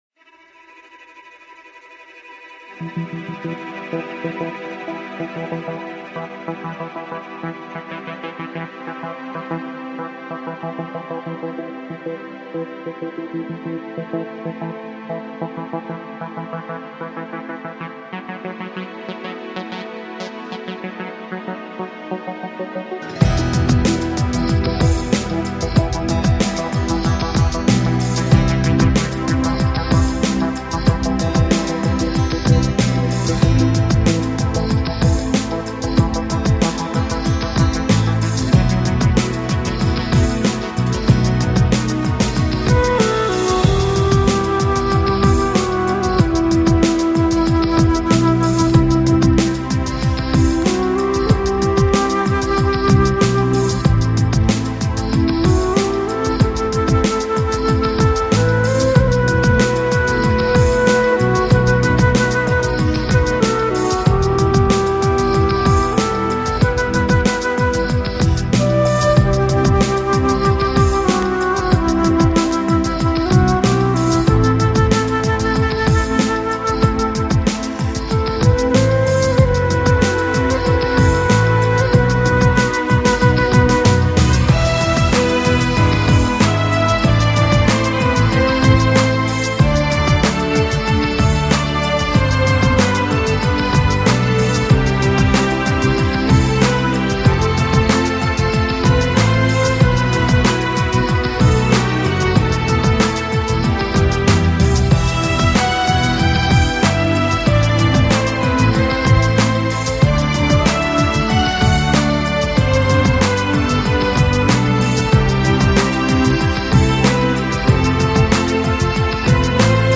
长笛演绎描绘一只蝴蝶优雅地飞行，弦乐，吉他，小提琴和钢琴丰富地烘托场景，令人陶醉在其中~
Styles: New Age